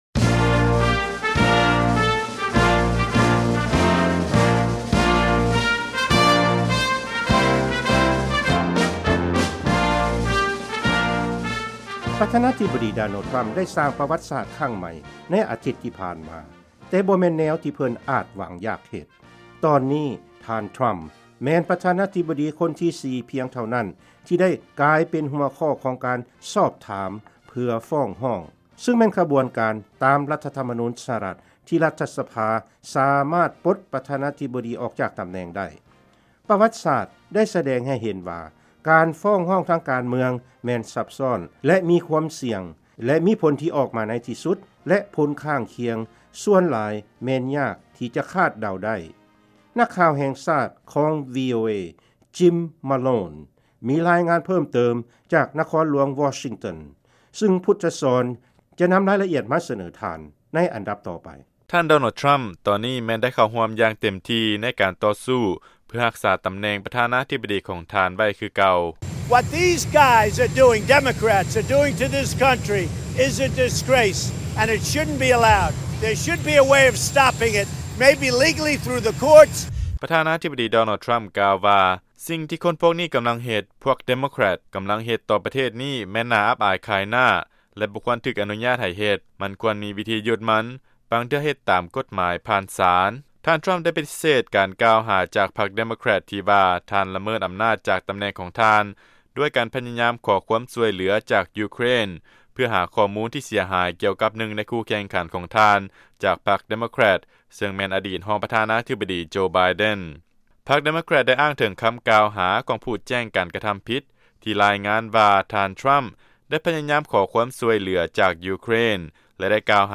ຟັງລາຍງານ ປະຫວັດສາດ ສະແດງໃຫ້ເຫັນວ່າ ການຕໍ່ສູ້ກັນ ໃນການຟ້ອງຮ້ອງ ມີຄວາມສ່ຽງ ແລະ ຄາດເດົາບໍ່ໄດ້